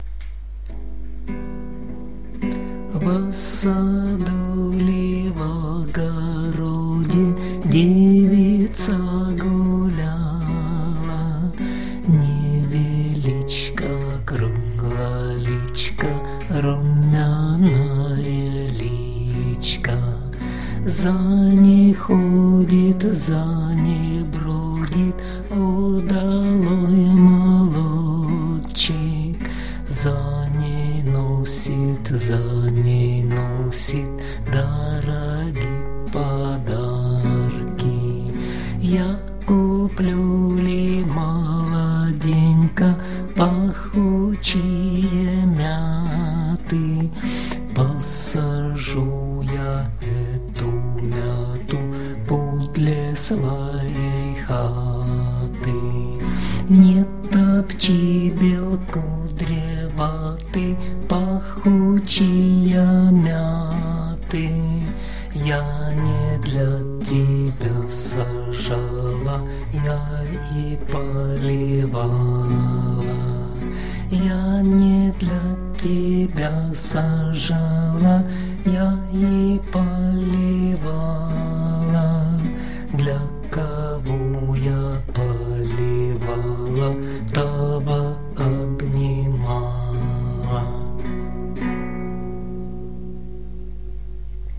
../icons/vospomin.jpg   Русская народная песня